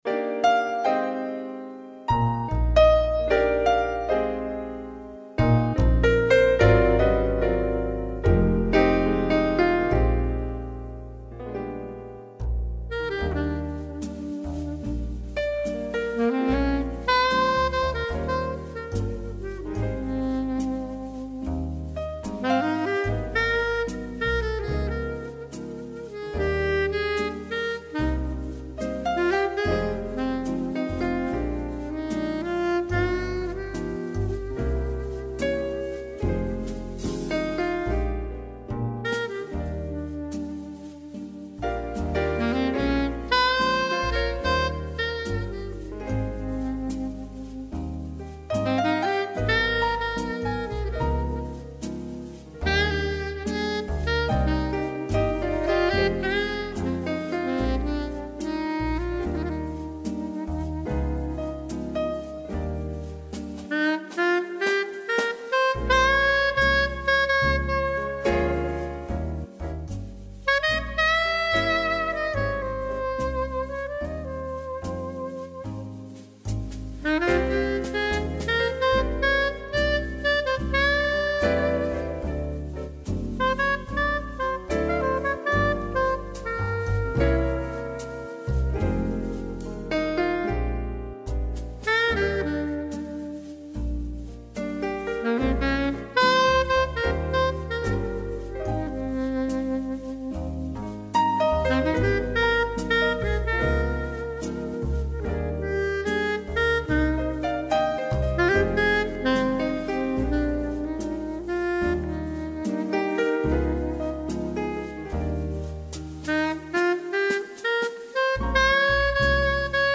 TOUT LE TALENT D’UN SAXOPHONISTE
Saxophones Sopranos :